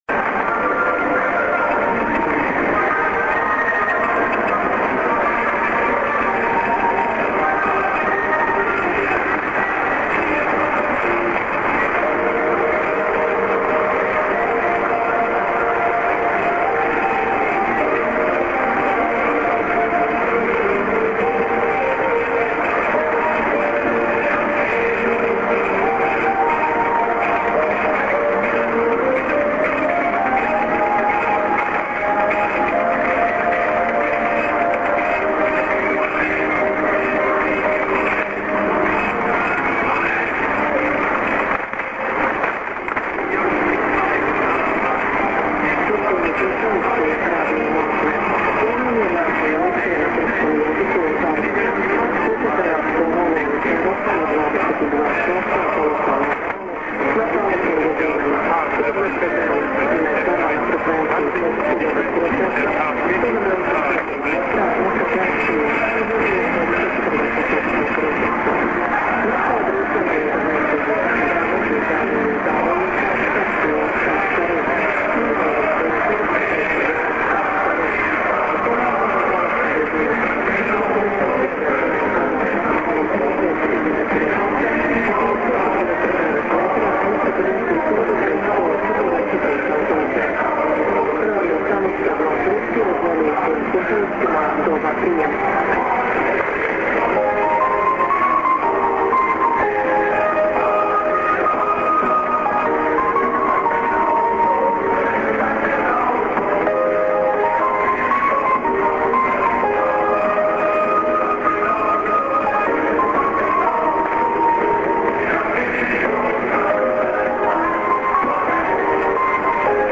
・このＨＰに載ってい音声(ＩＳとＩＤ等)は、当家(POST No. 488-xxxx)愛知県尾張旭市)で受信した物です。
V.O.Rusia->TWR->DW　V.O.Russia:IS->ID:Radio Moscow(women)->TWR:IS->+DW:IS->ID:DW(man)->